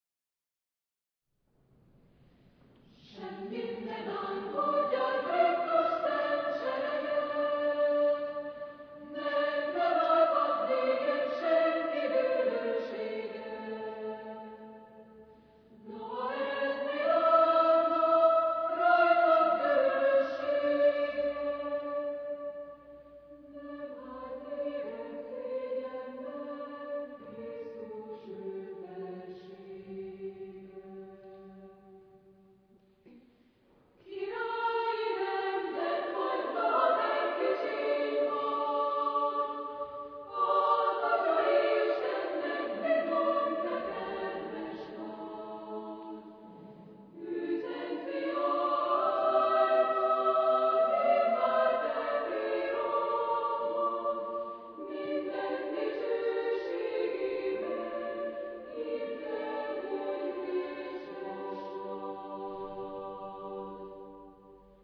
Epoque: 20th century  (1900-1949)
Genre-Style-Form: Sacred ; Psalm
Type of Choir: SSA  (3 children OR women voices )